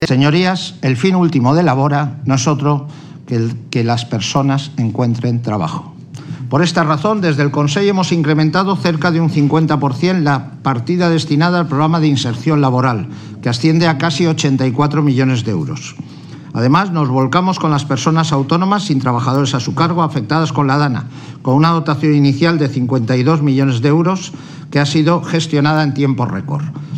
Rovira ha comparecido en la Comisión de Presupuestos de Les Corts, donde ha desgranado las cuentas de su departamento para 2025.